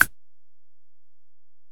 Perc (5).wav